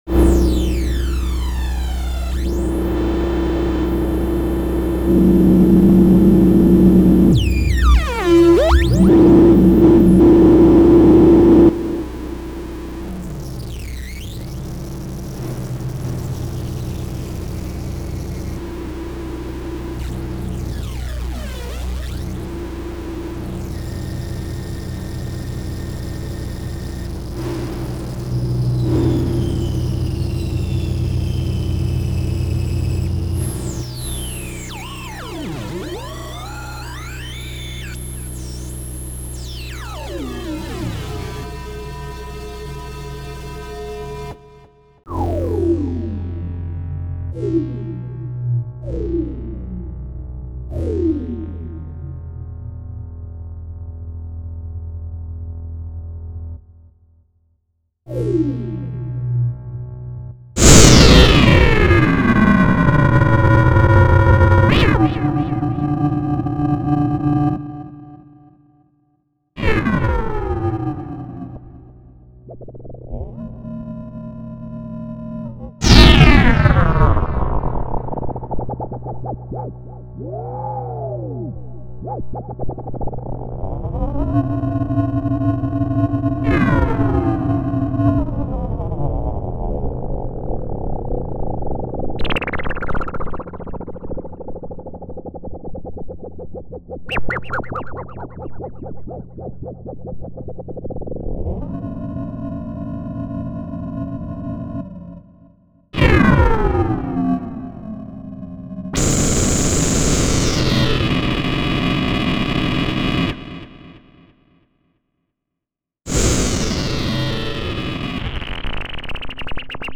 Dies ist die Grundlage für komplexe FM-Sounds.
Audio: wavetable_und_fm.mp3
fm.mp3